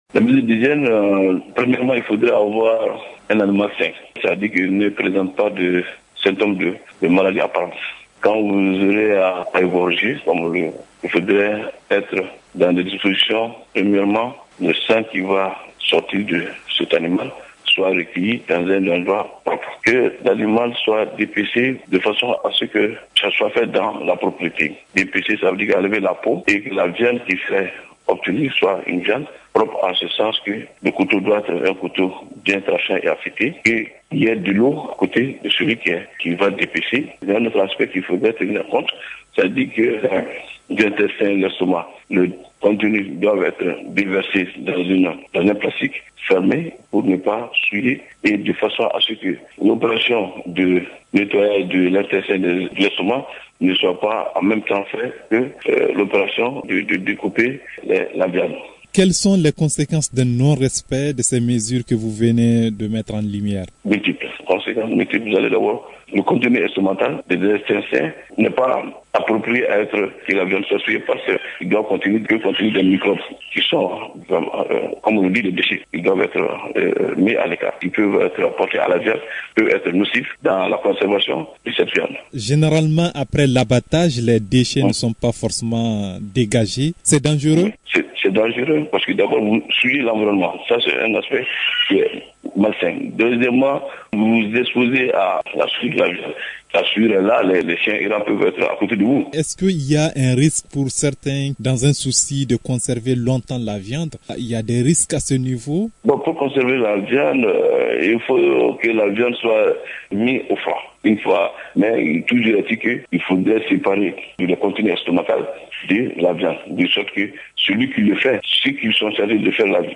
spécialiste en santé animale évoque quelques mesures d’hygiène à respecter.